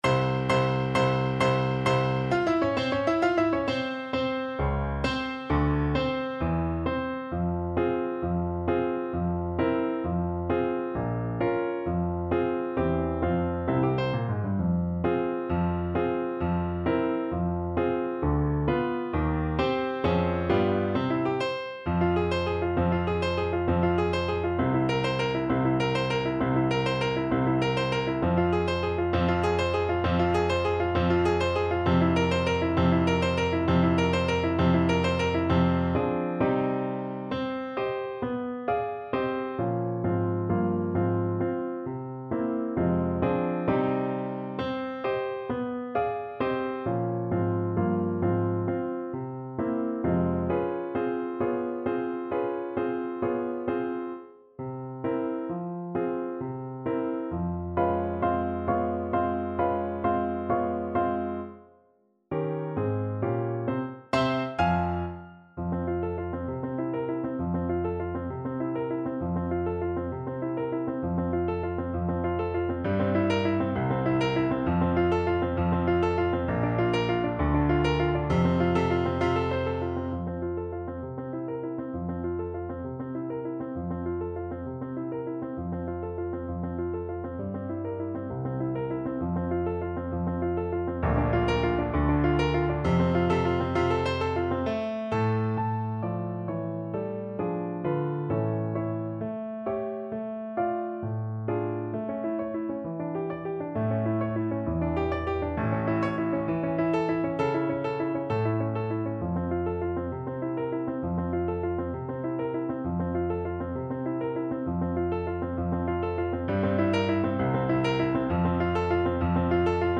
6/8 (View more 6/8 Music)
Classical (View more Classical Saxophone Music)